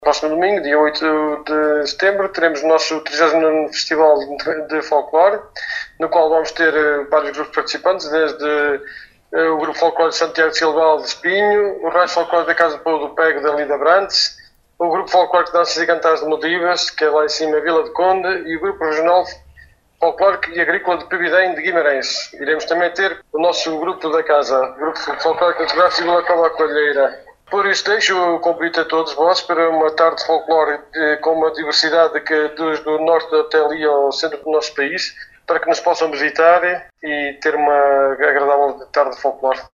em declarações à Alive FM